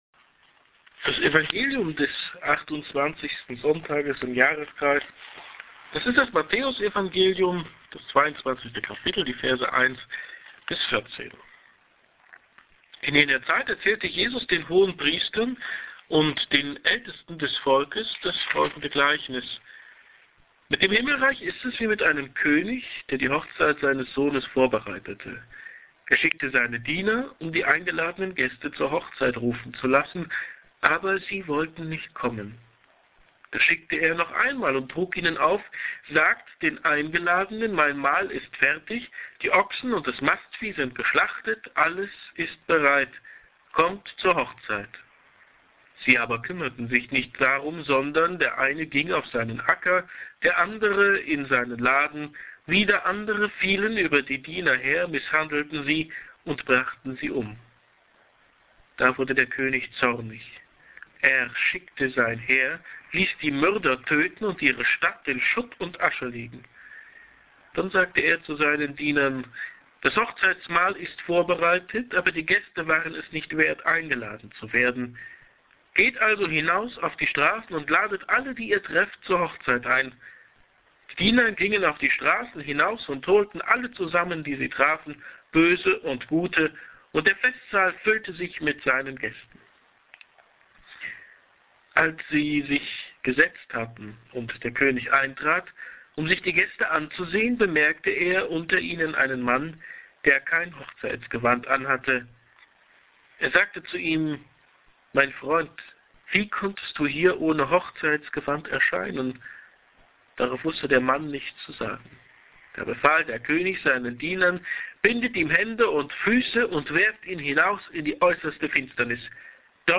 Underdressed – Predigt zum 28. So. i. Jkr. Lj. A
hier-klickt-die-predigt.mp3